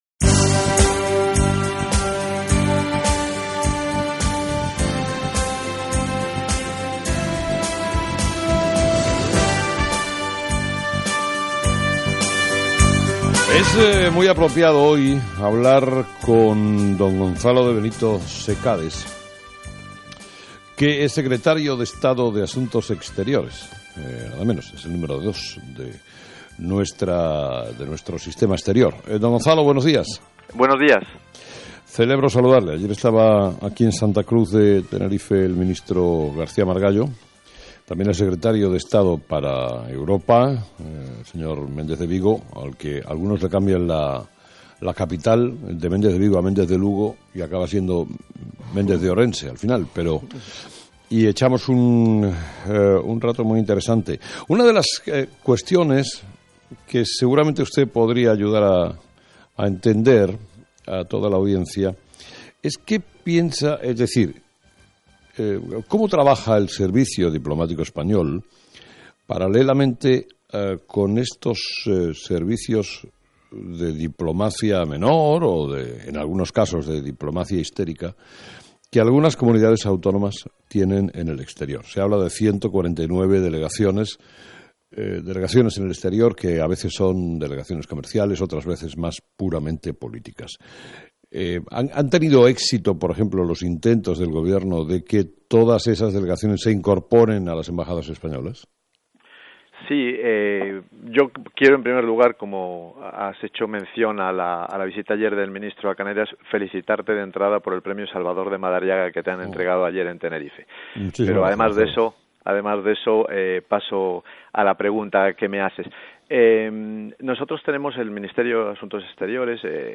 Entrevista del secretario de Estado Asuntos Exteriores en Onda Cero
Gonzalo de Benito interviene en el programa 'Herrera en la Onda' dirigido por Carlos Herrera